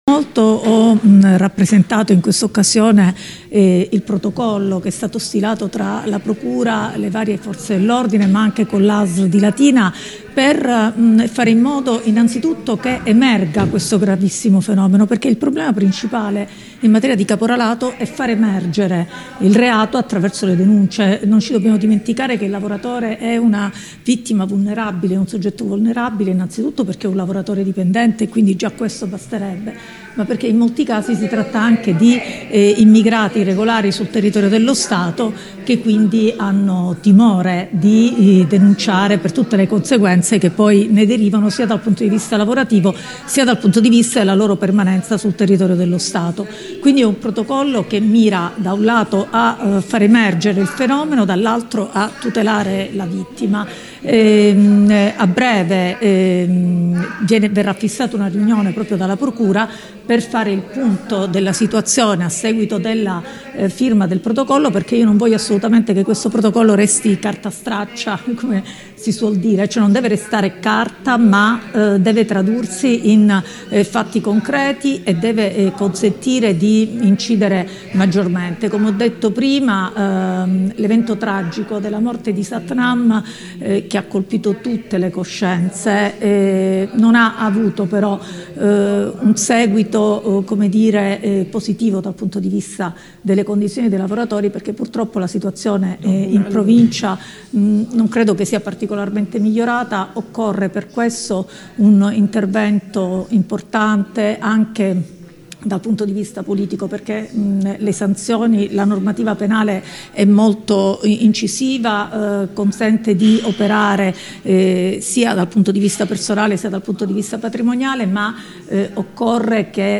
ha raccolto lì’intervisrta .